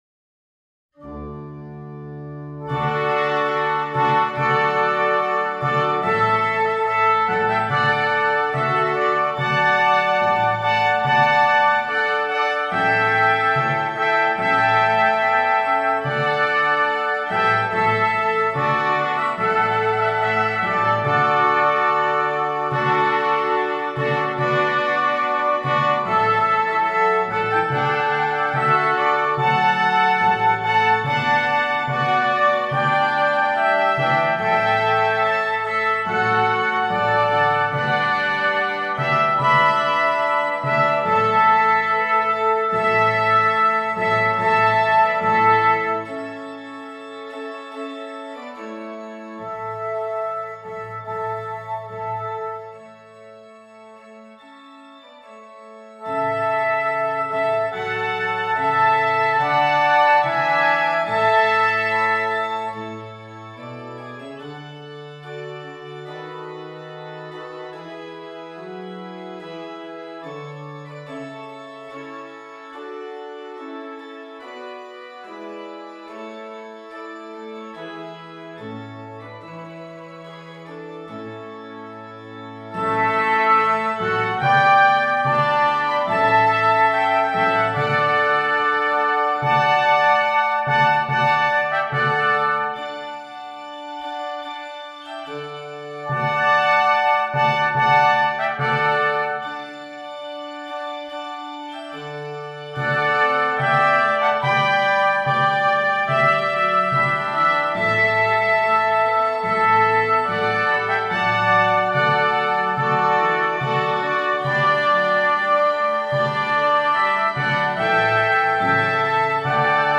3 Trumpets and Keyboard